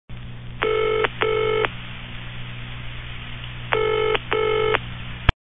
/32kbps) Описание: Гудки в телефоне ID 24942 Просмотрен 6555 раз Скачан 1880 раз Скопируй ссылку и скачай Fget-ом в течение 1-2 дней!